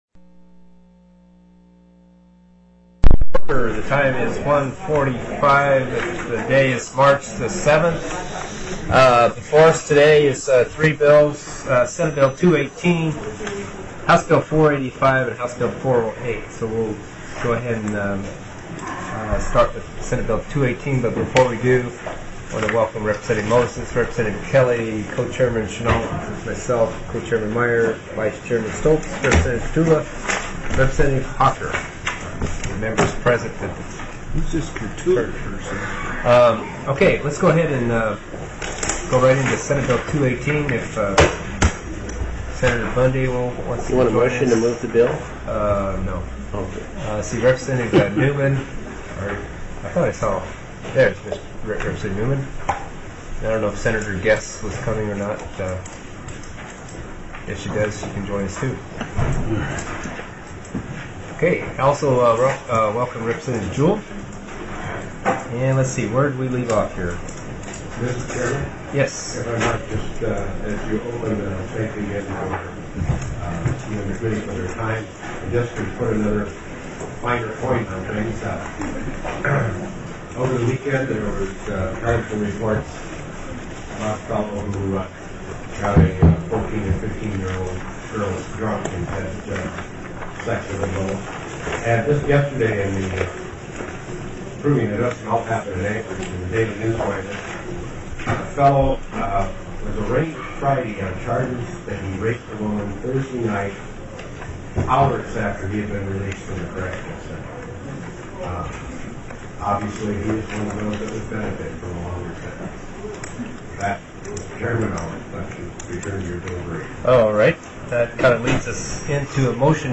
03/07/2006 01:30 PM House FINANCE